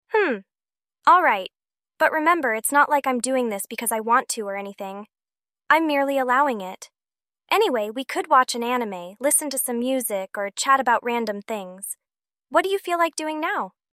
Voice Chat